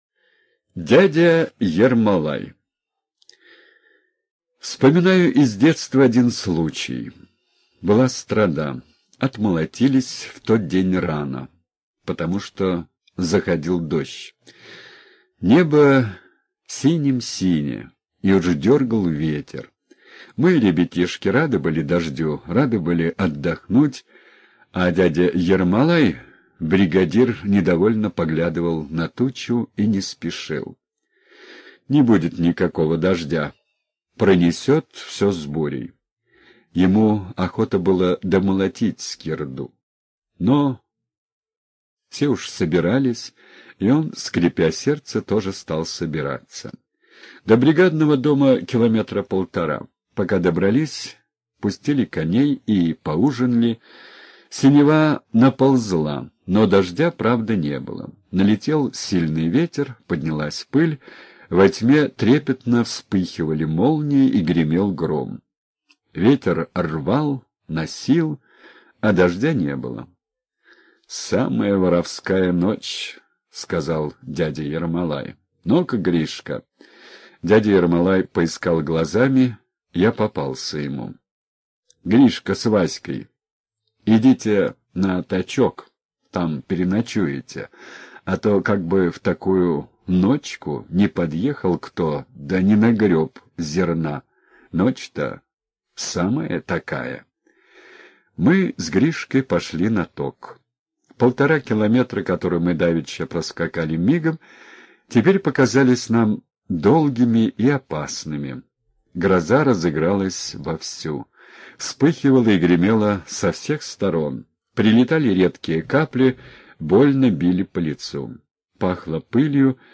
Дядя Ермолай — слушать аудиосказку Василий Шукшин бесплатно онлайн